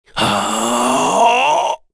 Dakaris-Vox_Casting2_kr.wav